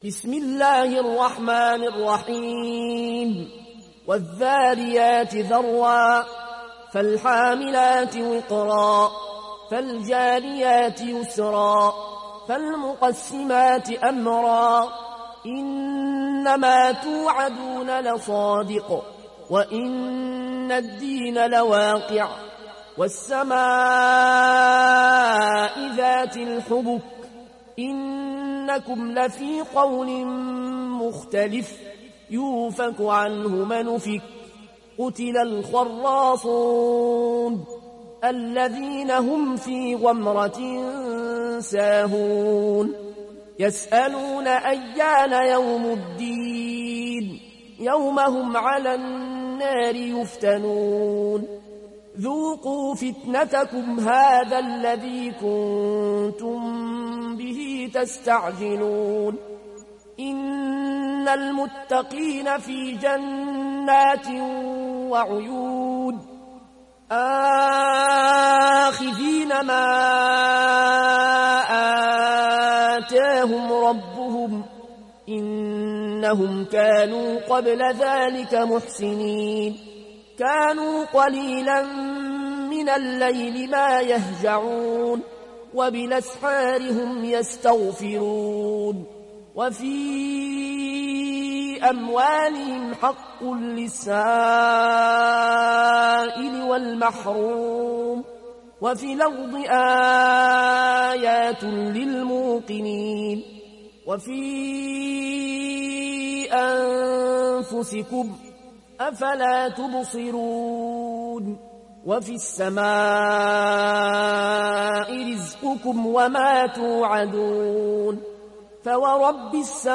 Sourate Ad Dariyat Télécharger mp3 Al Ayoune Al Koshi Riwayat Warch an Nafi, Téléchargez le Coran et écoutez les liens directs complets mp3